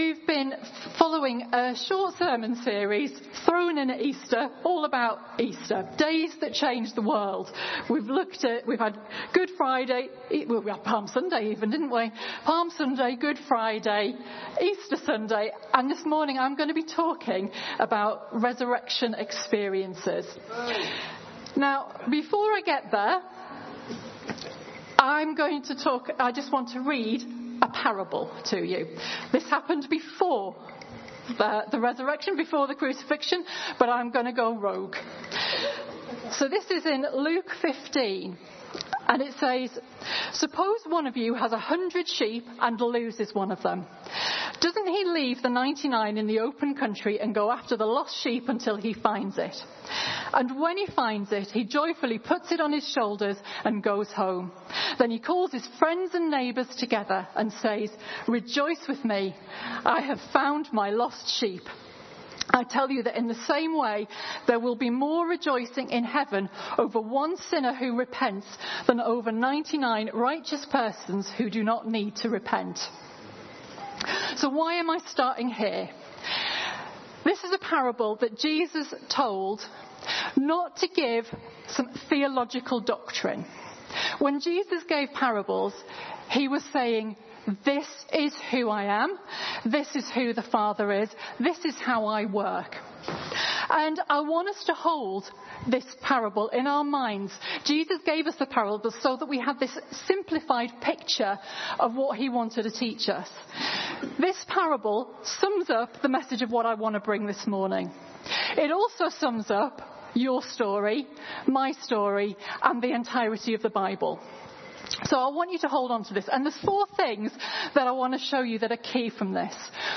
A sermon series at Emmanuel Church Durham for Easter 2025